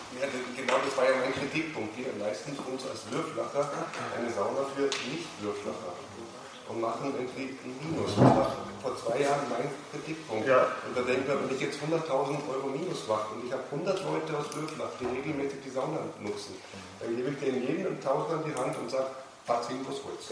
Dass die Gemeinderatssitzung von uns und der SPÖ Würflach dazu genutzt wurde, auf sachlicher Ebene Fragen zu stellen, die über die Farbe der Sonnenliegen hinausgehen, hätte auch die Würflacher ÖVP nicht überraschen dürfen.